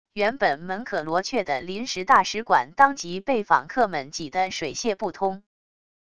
原本门可罗雀的临时大使馆当即被访客们挤得水泄不通wav音频生成系统WAV Audio Player